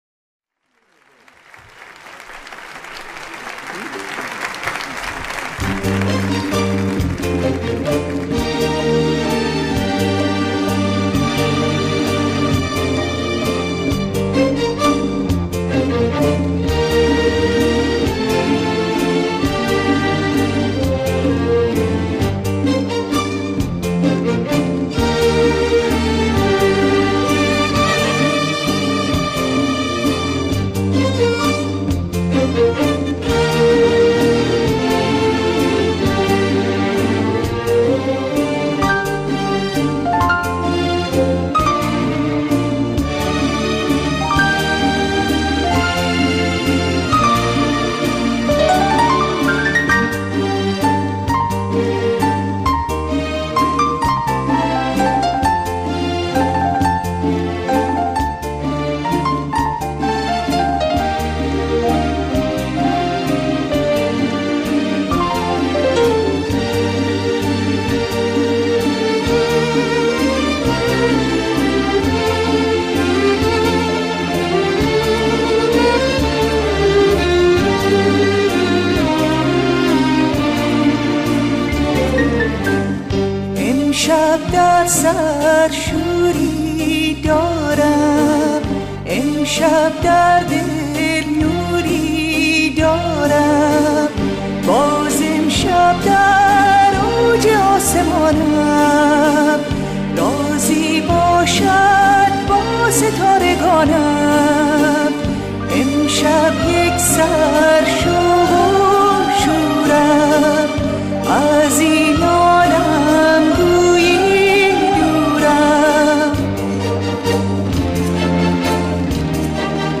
در پرده شور